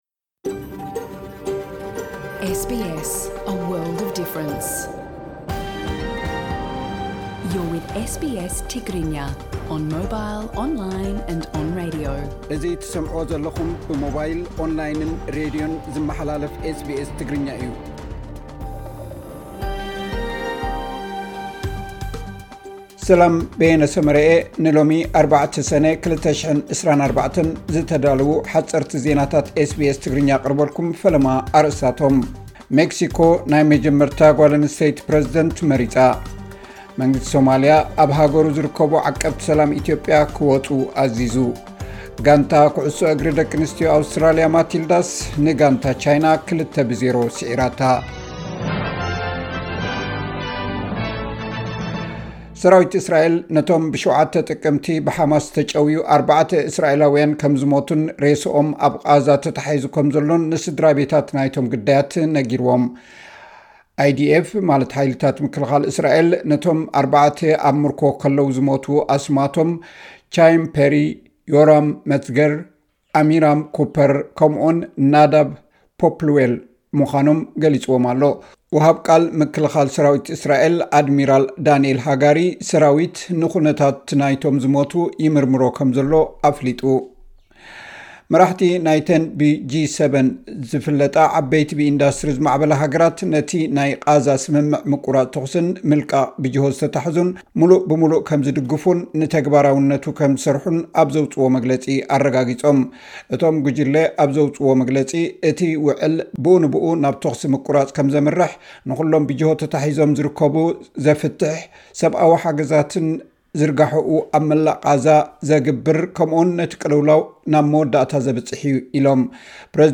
ሓጸርቲ ዜናታት ኤስ ቢ ኤስ ትግርኛ (04 ሰነ 2024)